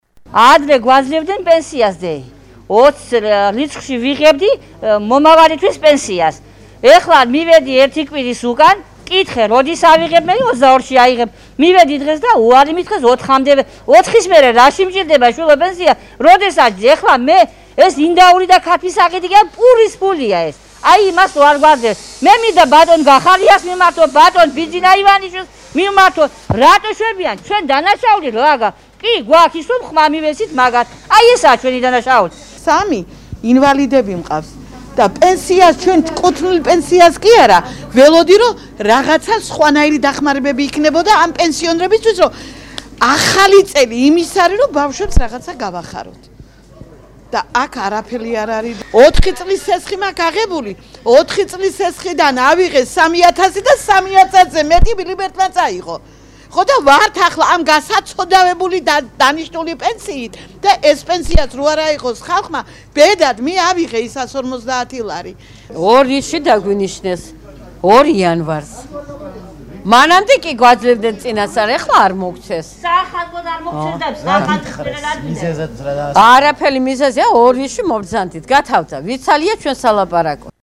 მოვისმინოთ პენსიონერების ხმები